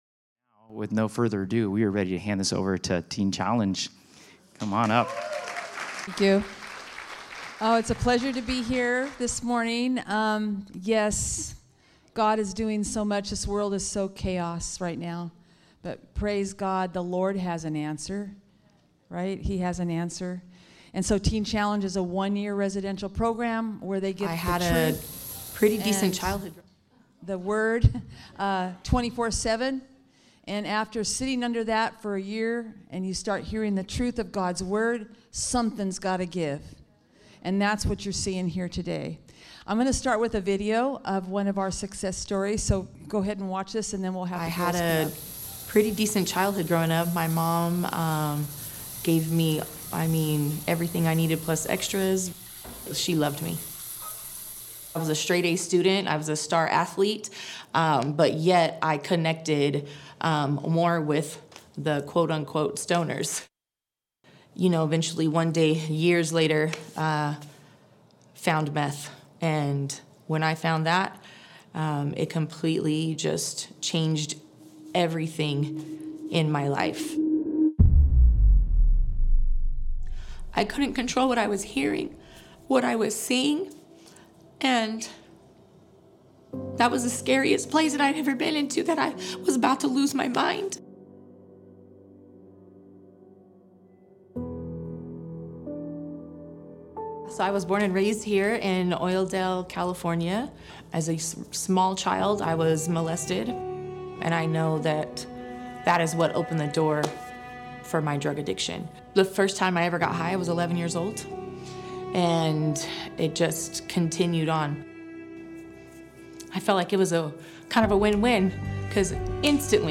This Sunday Teen Challenge will be leading our Sunday morning service.